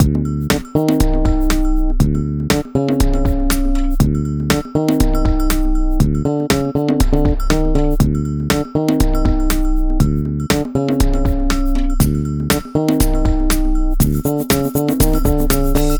Royalty free short music loop, to ad professionalism to your sites, presentations & projects